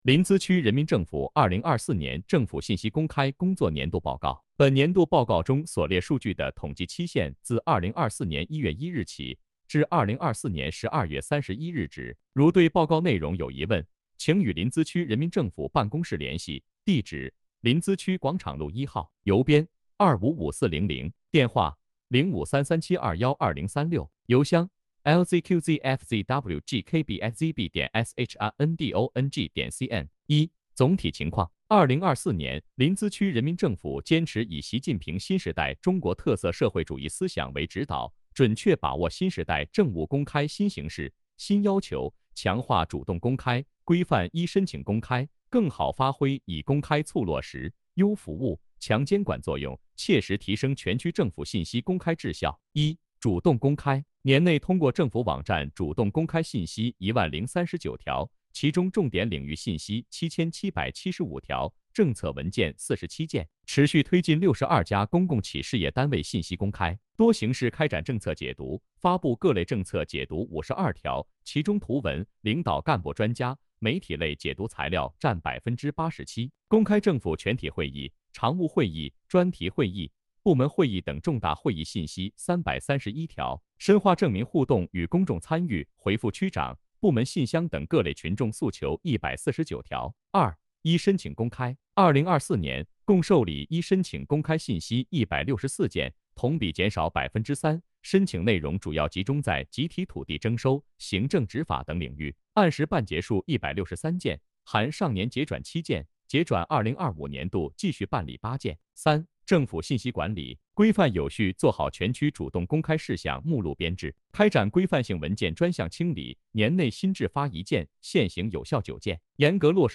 语音播报